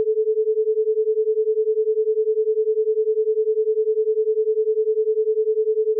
Example 3: 10Hz Monaural Beat
Two tones presented monaurally (430Hz and 440Hz)